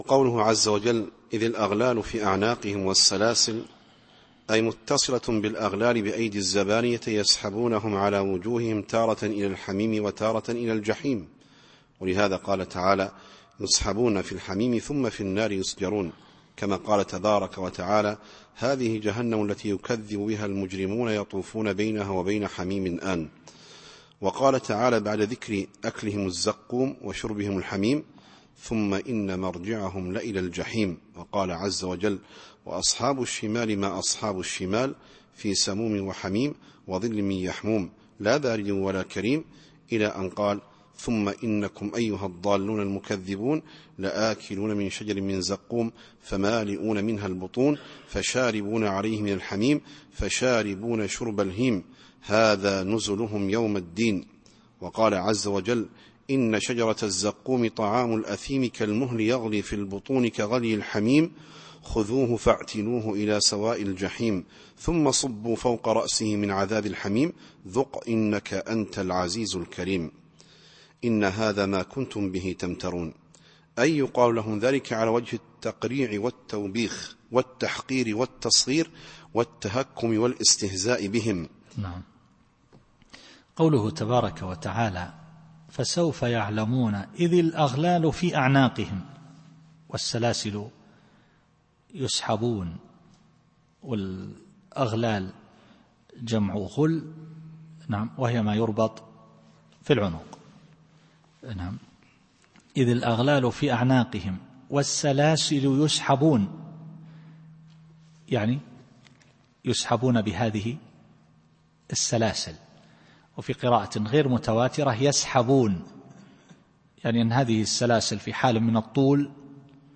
التفسير الصوتي [غافر / 71]